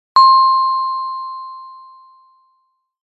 Звуки правильного и неправильного ответа
Звук: успешное признание